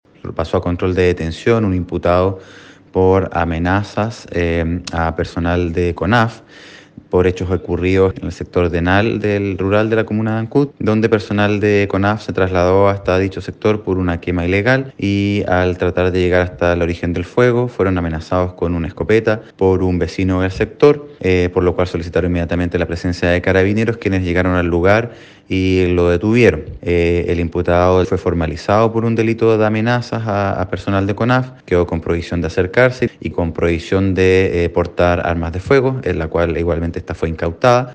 El fiscal Luis Barría, de la Fiscalía local de Ancud, sostuvo que fue mediante el uso de una escopeta que este sujeto amedrentó al personal de la corporación nacional forestal.